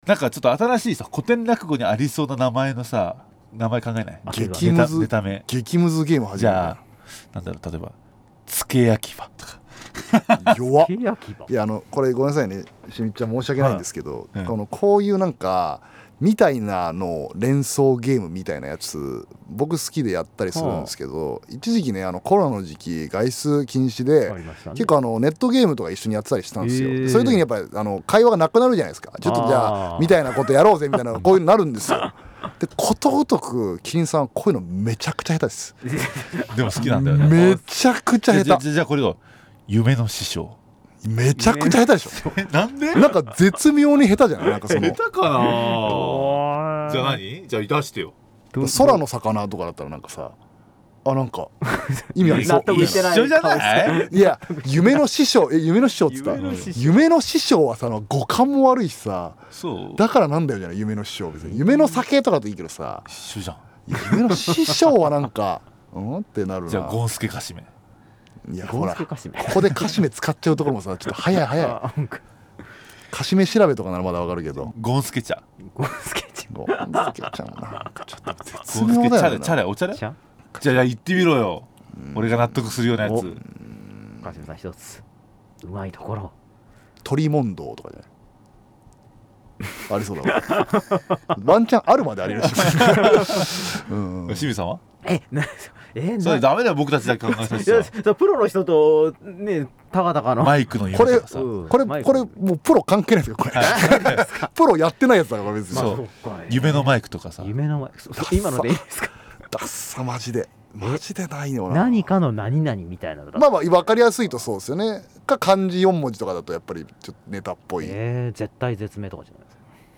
【調点】未公開トーク
しかし、お２人が本気になってしまいラジオにもかかわらず沈黙が続いたので、 放送の中ではカットしました。